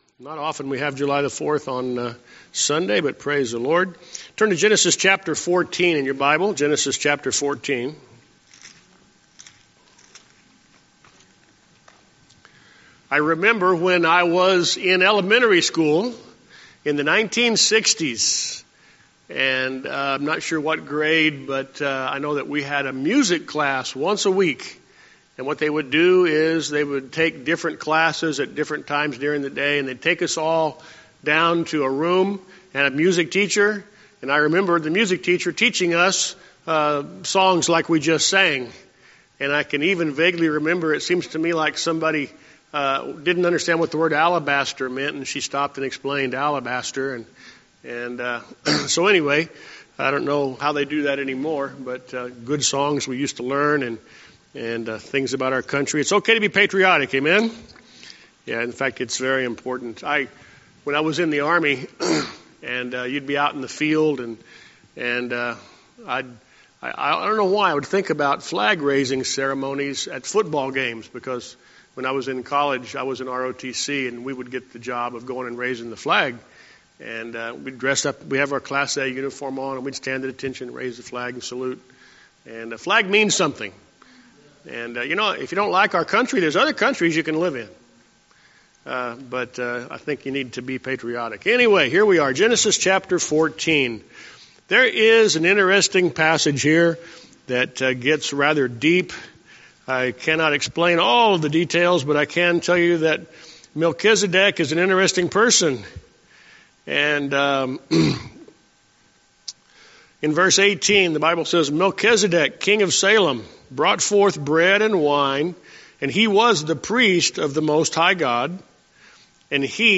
Sunday School Recordings